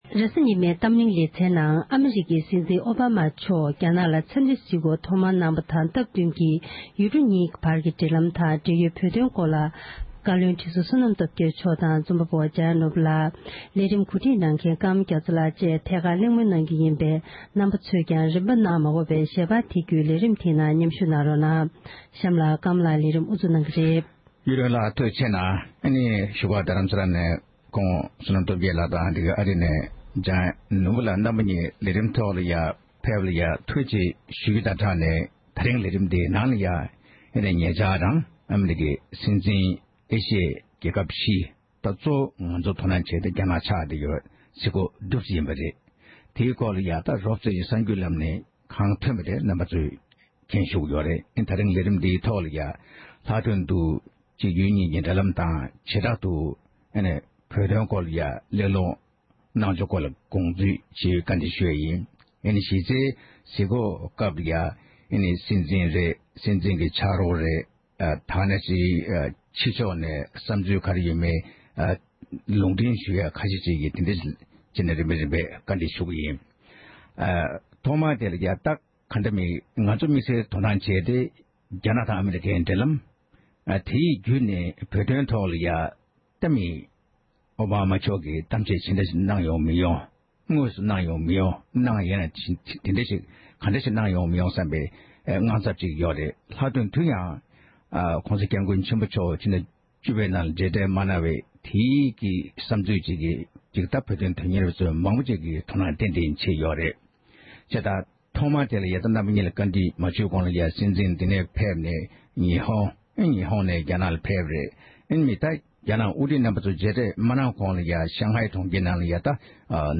གཏམ་གླེང